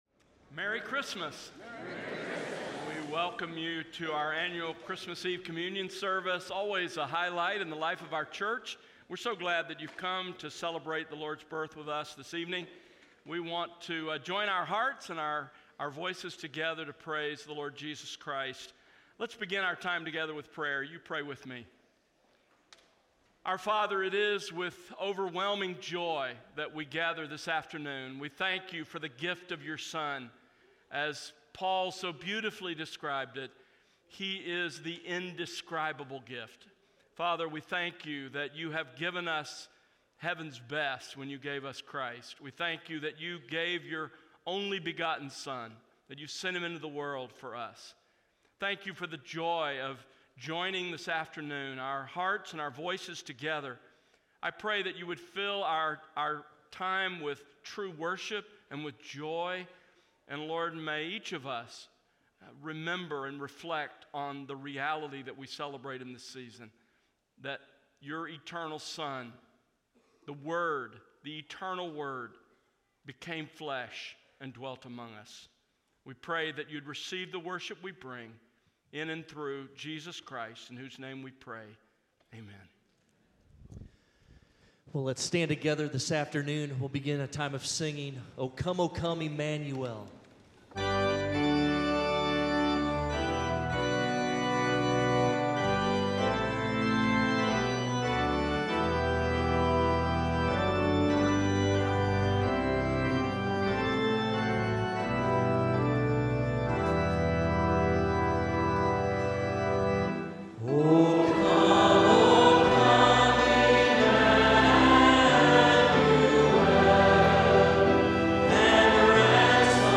Christmas Eve Communion Service